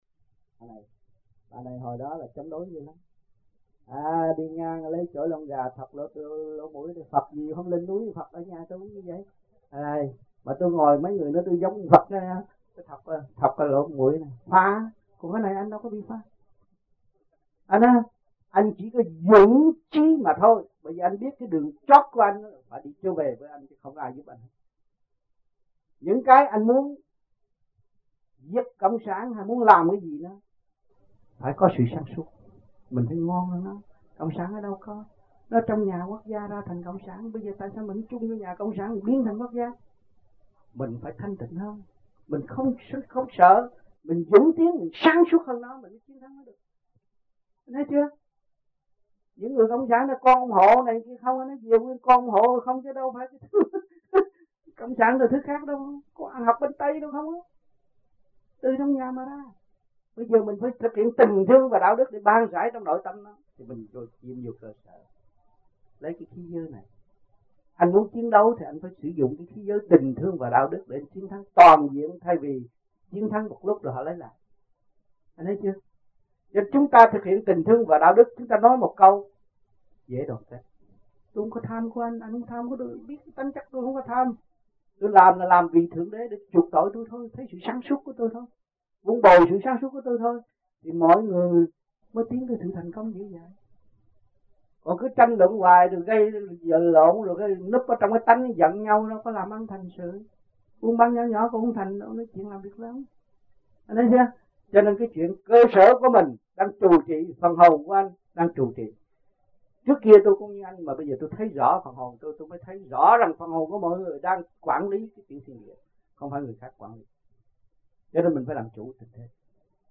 Fountain Valley, California, United States Trong dịp : Sinh hoạt thiền đường >> wide display >> Downloads